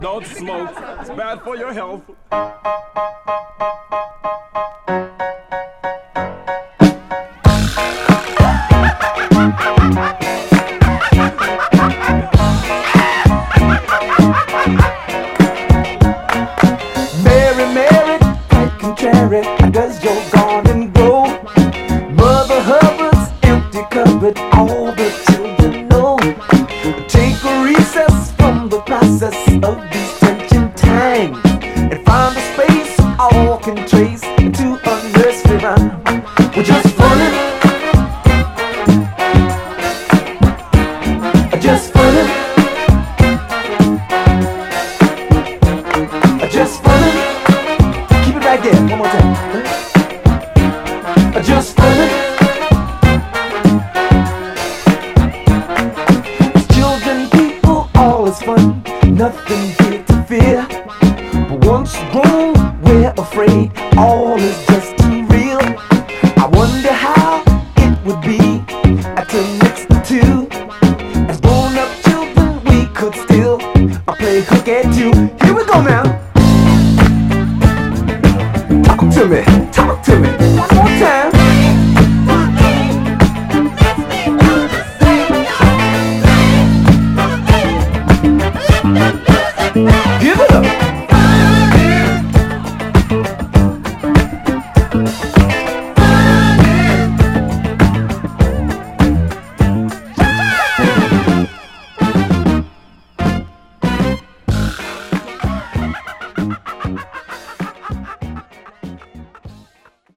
強力なモダン・ファンク/ミッド・ブギーです！
※試聴音源は実際にお送りする商品から録音したものです※